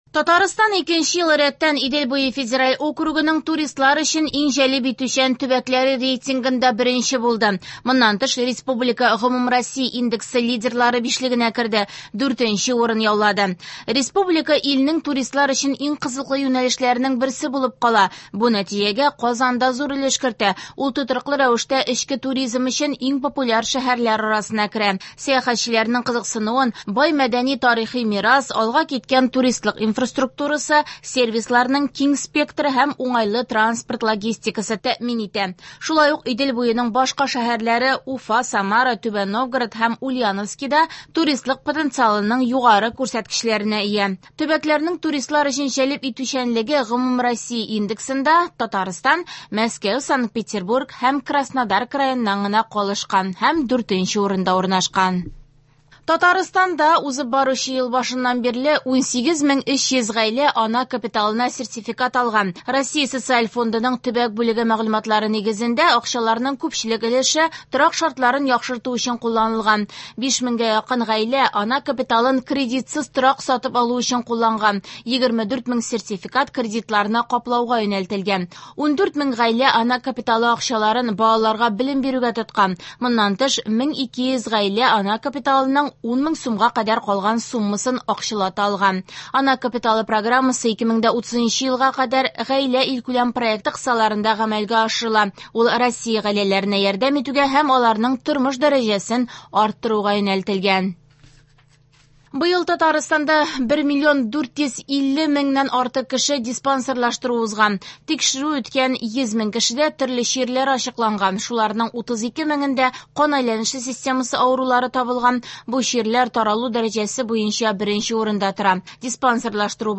Яңалыклар (29.12.25)